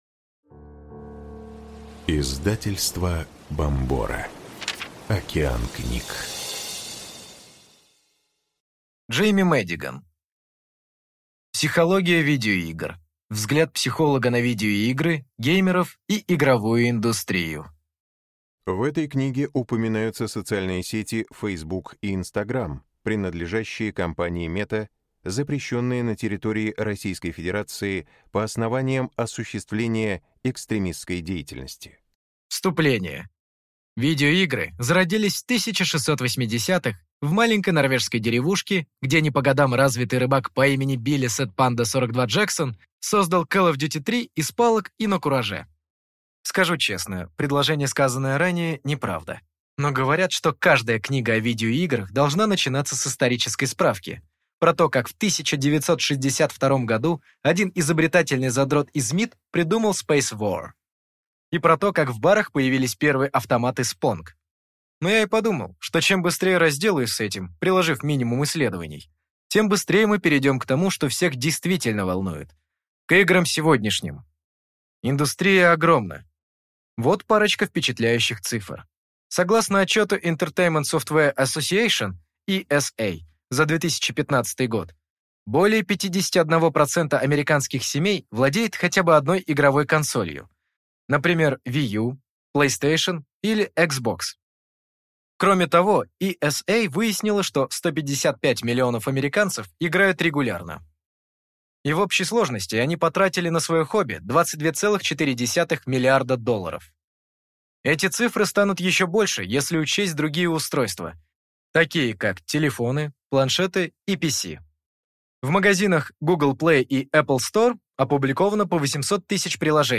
Аудиокнига Психология видеоигр. Взгляд психолога на видеоигры, геймеров и игровую индустрию | Библиотека аудиокниг